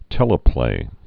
(tĕlə-plā)